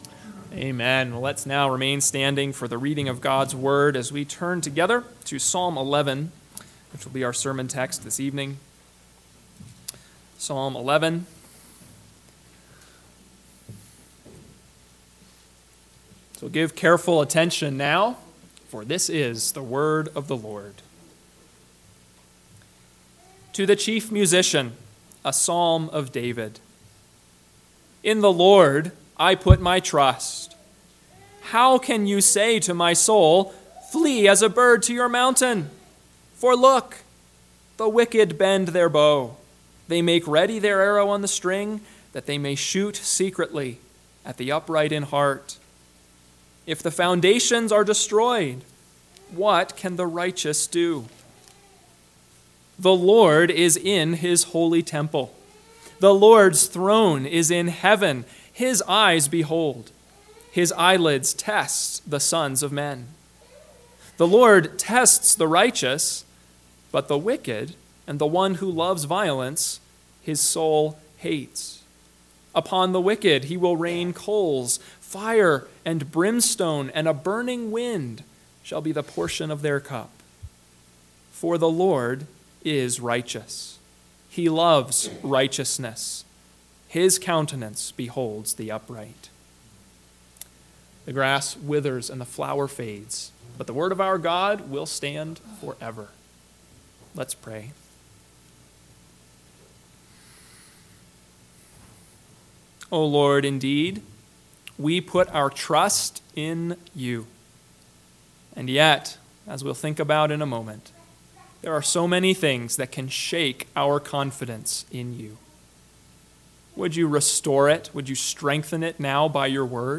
PM Sermon – 5/12/2024 – Psalm 11 – Northwoods Sermons